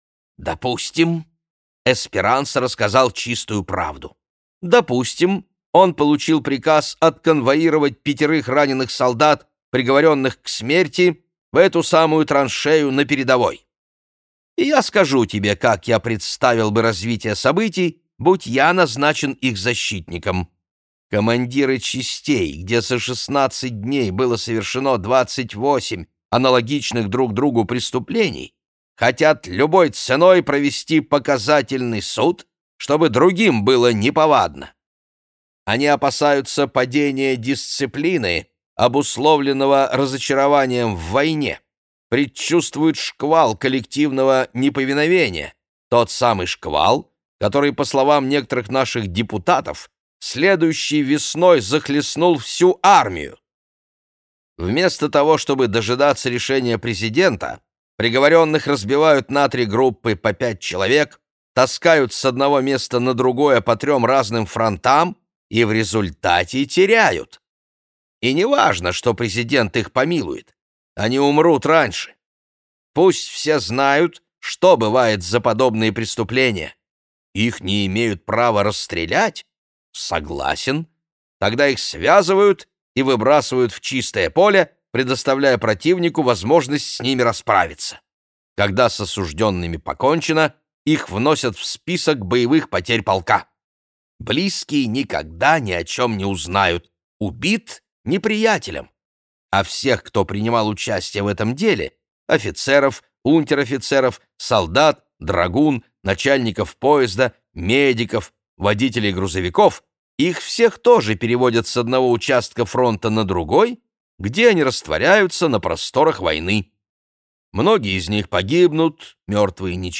Аудиокнига Долгая помолвка | Библиотека аудиокниг
Прослушать и бесплатно скачать фрагмент аудиокниги